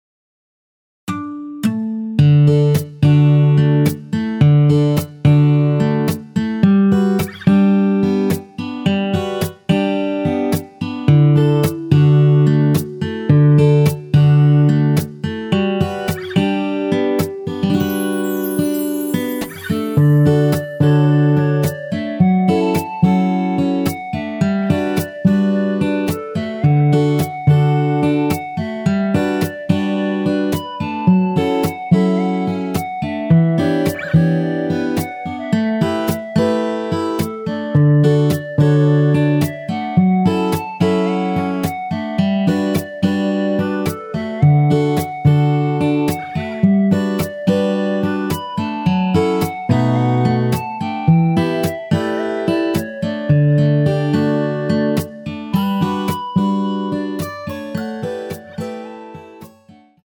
원키에서(+7)올린 멜로디 포함된 MR입니다.
엔딩이 페이드 아웃이라 마지막 음~ 2번 하고 엔딩을 만들어 놓았습니다.(일반MR 미리듣기 참조)
앞부분30초, 뒷부분30초씩 편집해서 올려 드리고 있습니다.
중간에 음이 끈어지고 다시 나오는 이유는
(멜로디 MR)은 가이드 멜로디가 포함된 MR 입니다.